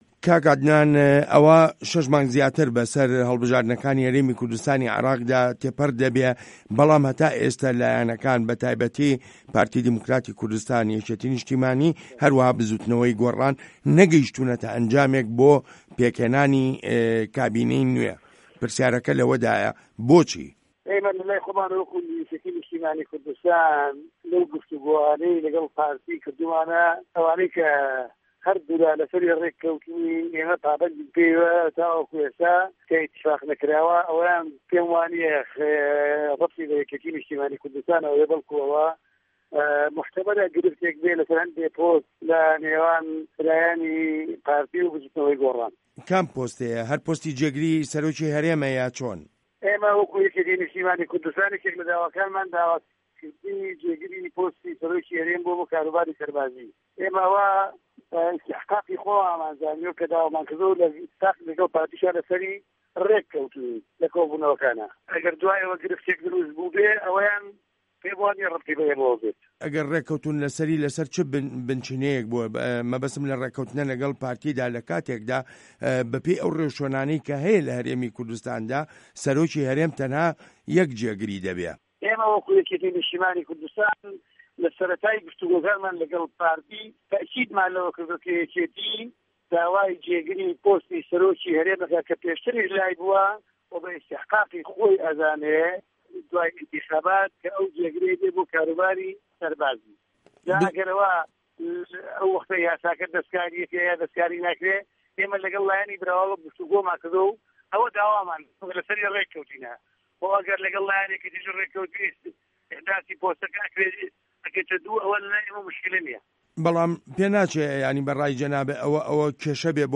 زانیاری زیاتر لە دەقی وتووێژەکەدایە: